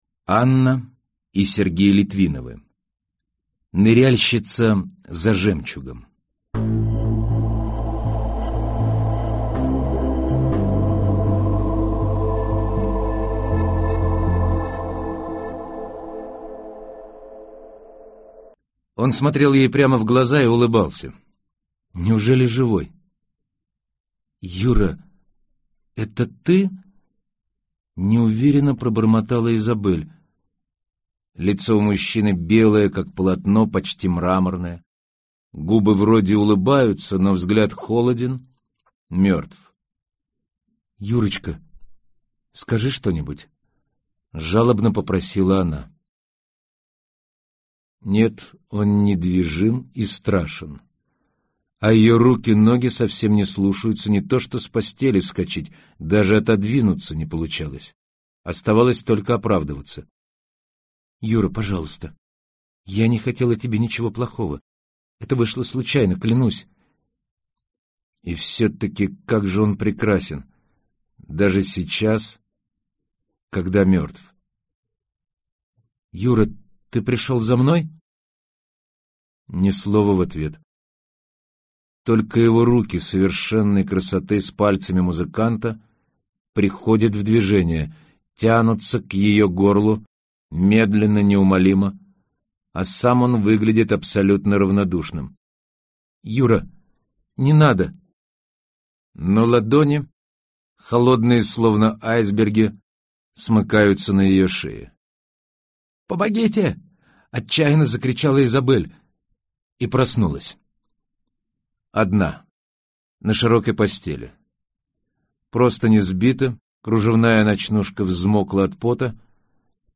Аудиокнига Ныряльщица за жемчугом | Библиотека аудиокниг